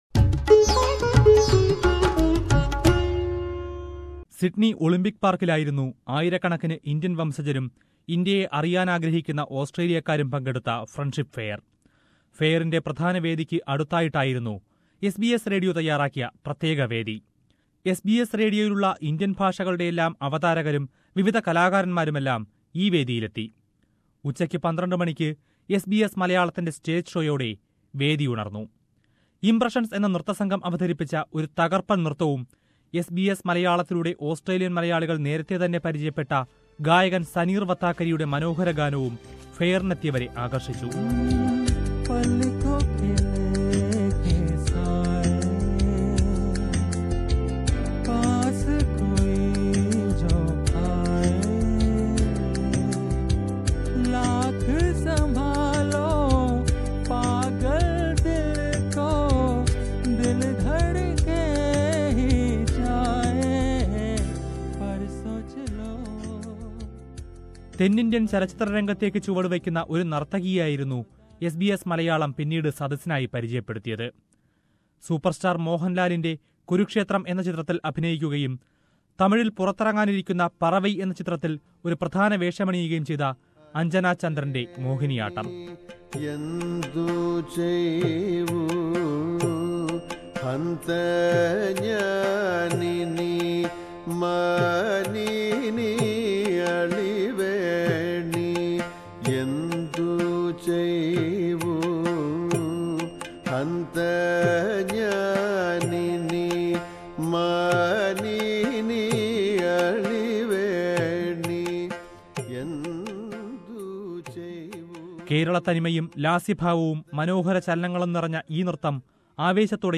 The Indian diaspora in Sydney celebrated the relationship between both the countries last week. SBS Malayalam had a special stage in the Fair, where performers and public came together to celebrate. A special report on the special event...